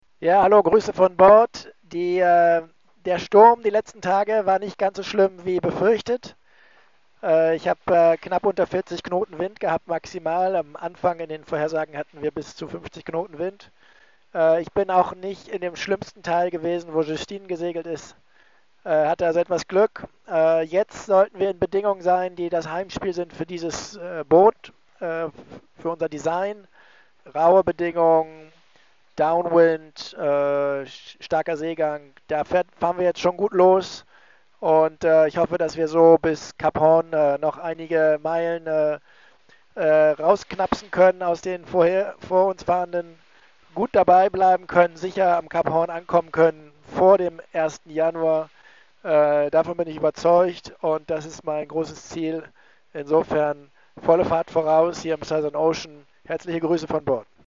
Im aktuellen Audiofile von Bord bestätigt er, das „Heimspiel“ für Malizia: „Raue Bedingungen, Downwind, starker Seegang. Da fahren wir jetzt schon gut los…Volle Fahrt voraus.“: